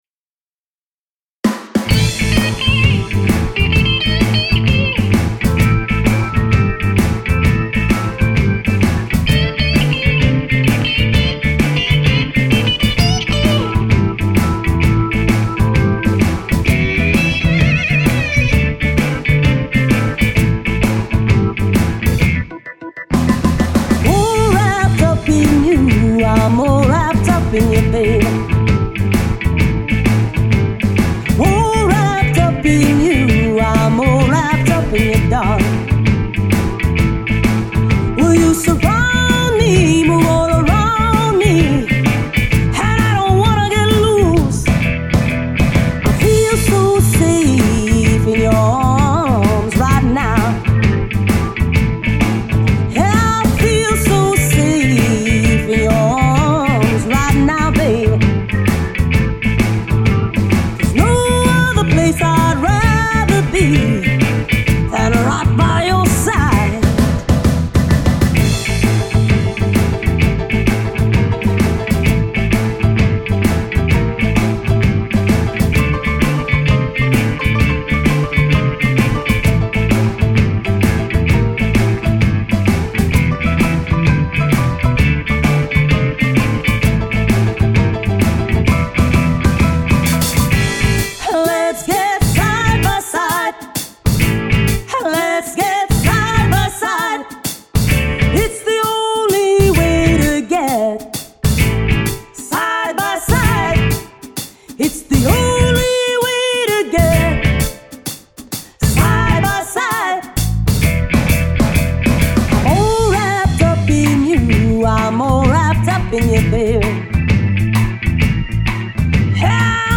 This is a Blues Tune.